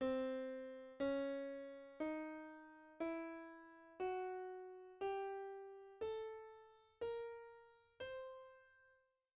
The second mode, also called the octatonic, diminished, whole-half, or half-whole scale, is divided into four groups of three notes each. It contains the intervals semitone, tone, semitone, tone, semitone, tone, semitone, tone – it has three transpositions, like the diminished 7th chord, and two modes: